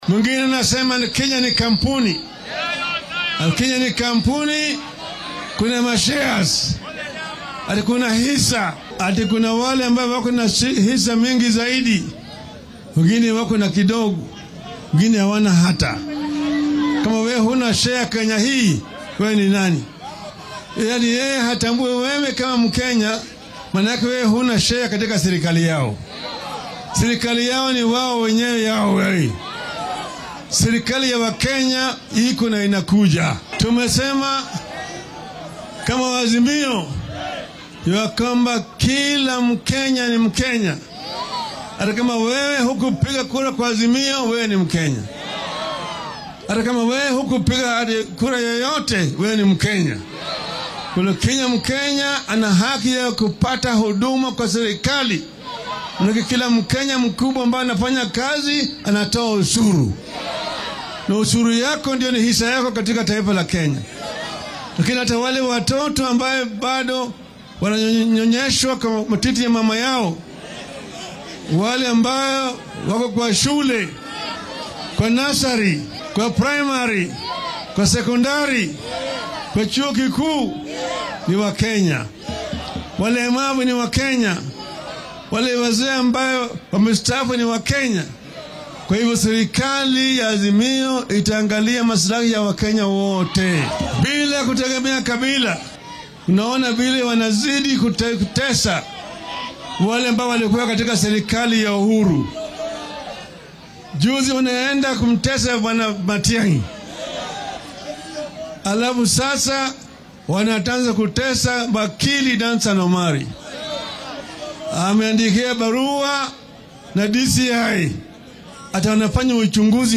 Madaxa isbeheysiga mucaaradka ee Azimio La Umoja-One Kenya, Raila Odinga ayaa maanta kulan siyaasadeed oo horay loogu tilmaamay munaasabad duco ku qabtay xarunta Jeevanjee Gardens ee magaalada Nairobi.